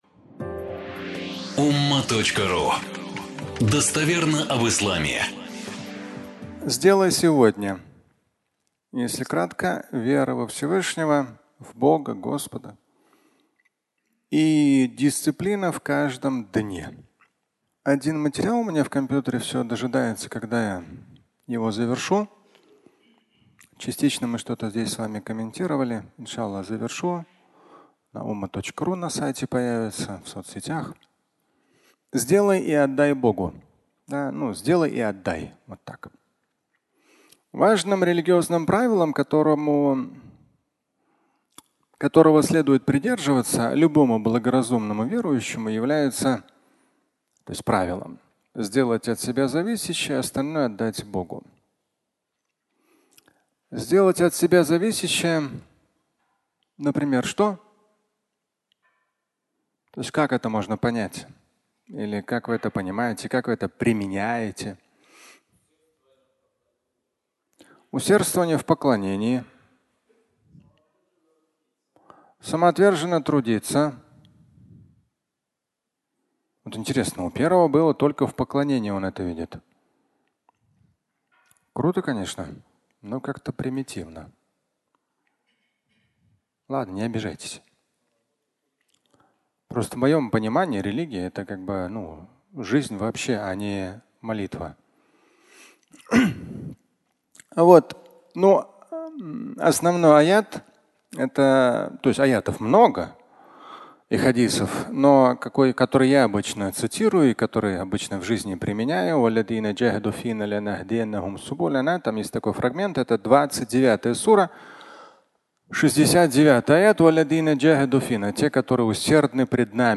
Сделать сегодня (аудиолекция)